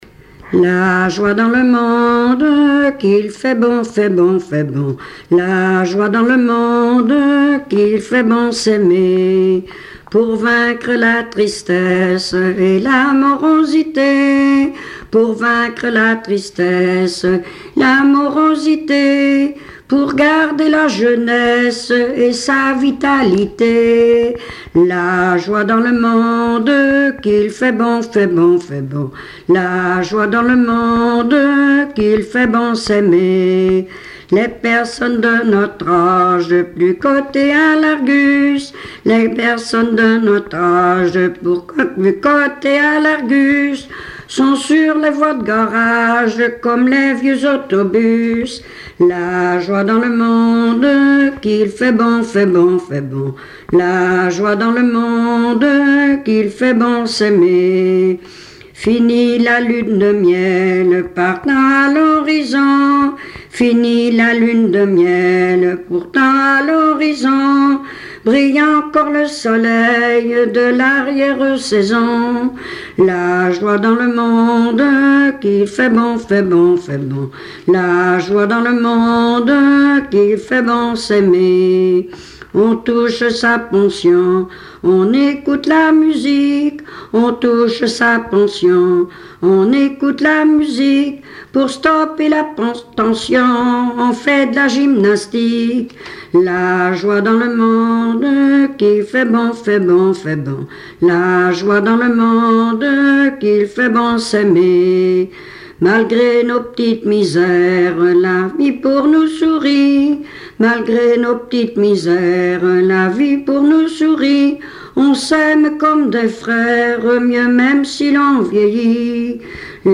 Saint-Martin-des-Noyers
Chansons traditionnelles et populaires
Pièce musicale inédite